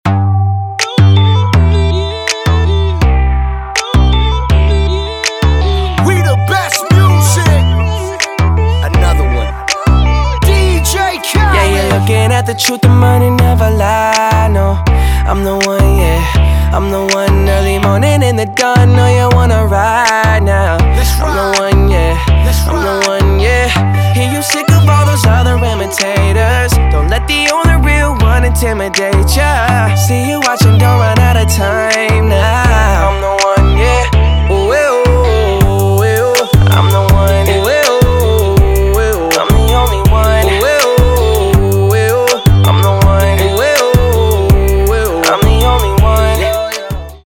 Хип-хоп
RnB
vocal